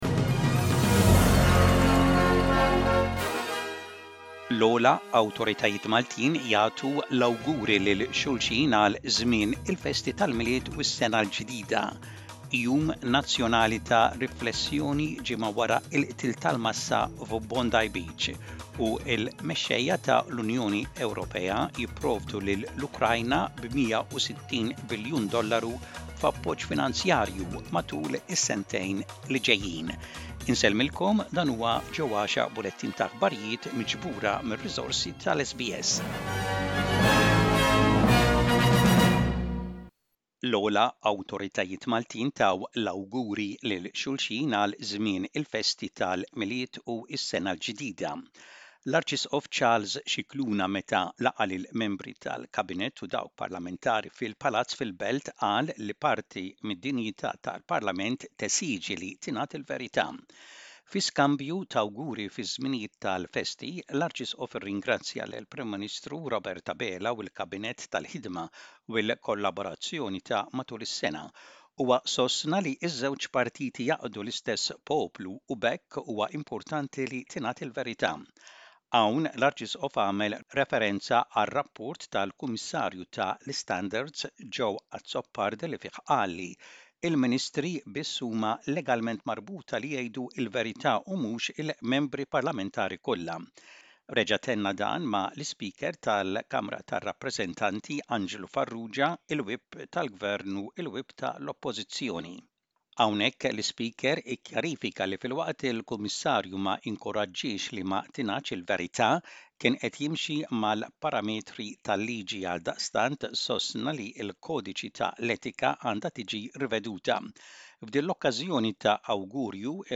SBS Maltese News - Image SBS Maltese